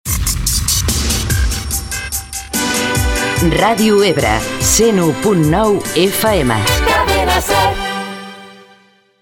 Identificació de l'emissora i freqüència